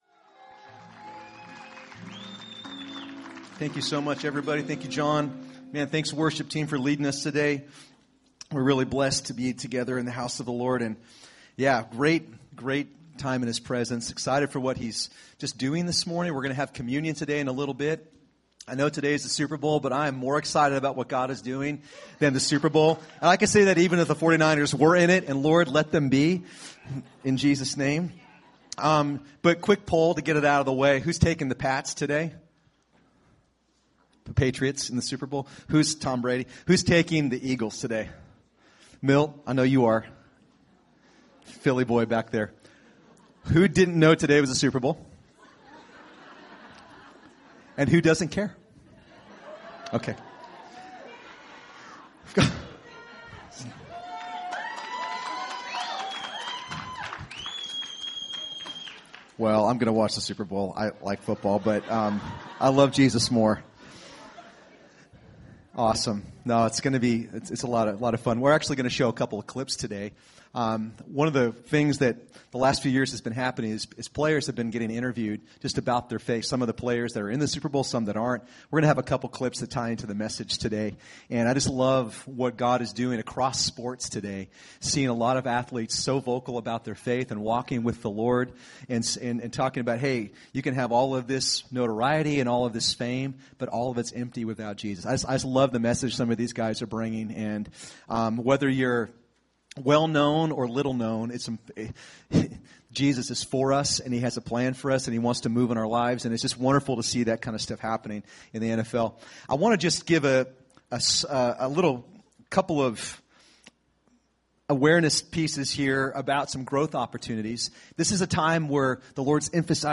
Recorded at New Life Christian Center, Sunday, February 4, 2018 at 11 AM.